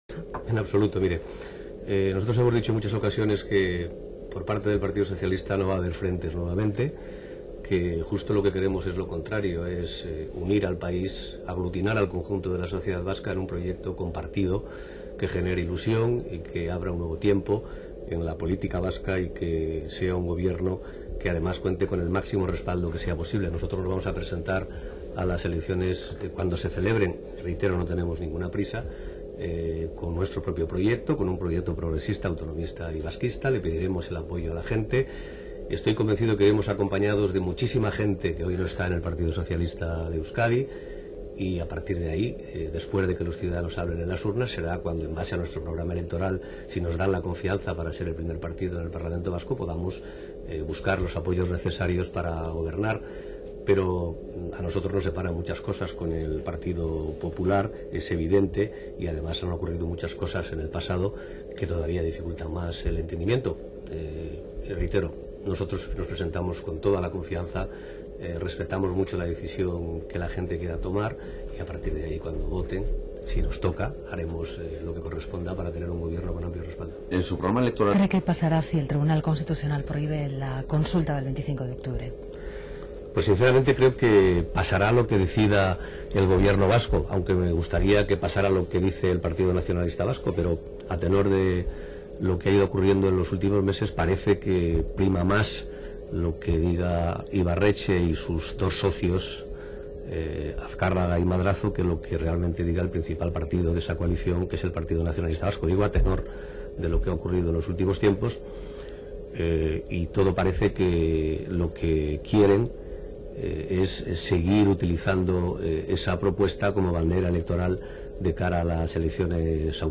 En una entrevista concedida a Radio Euskadi,